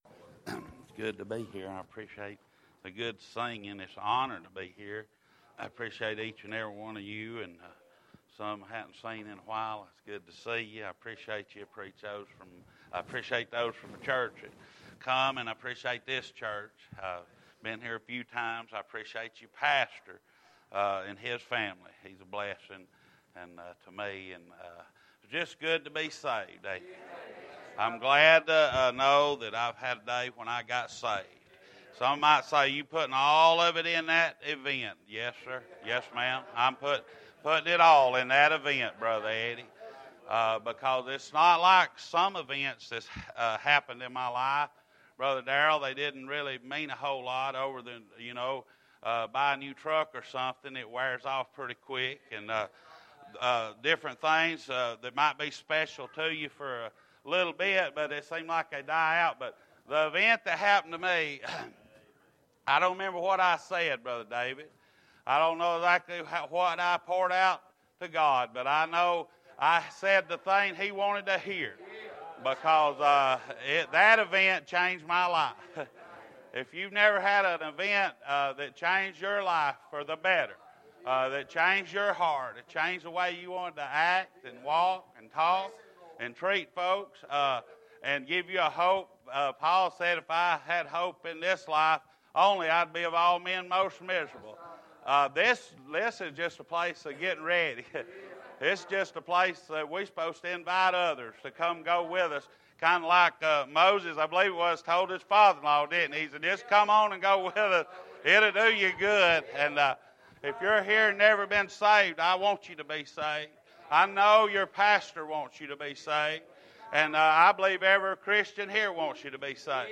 Welcome Grove Baptist Church, Marietta GA
Sermon media